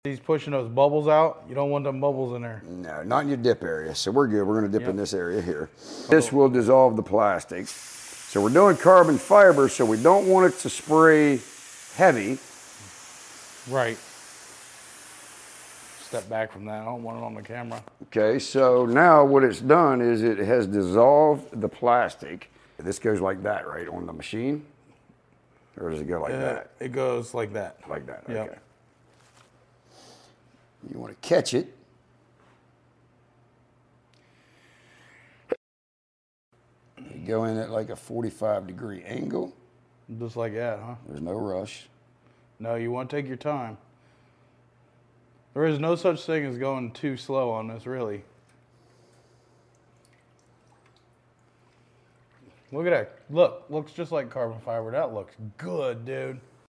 Hydro dipping carbon fiber sound effects free download